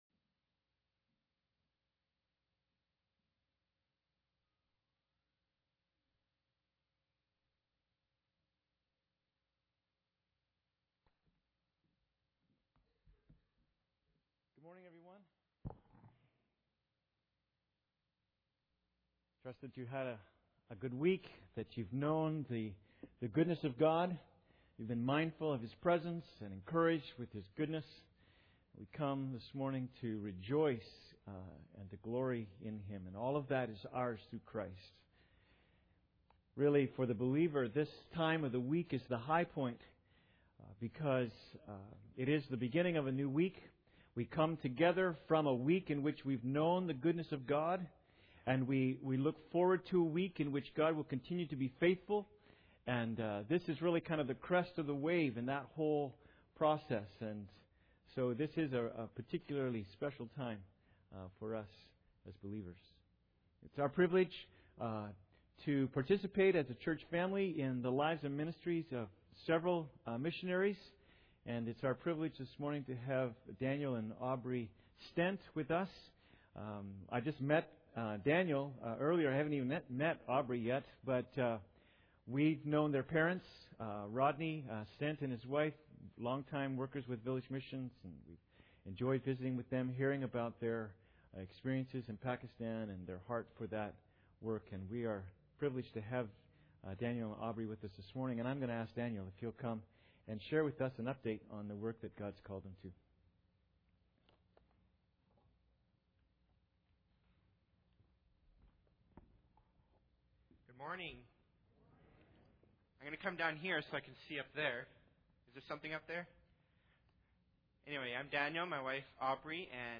Ephesians 3:14-19 Service Type: Sunday Service Introduction I. Pursue the empowering of the Spirit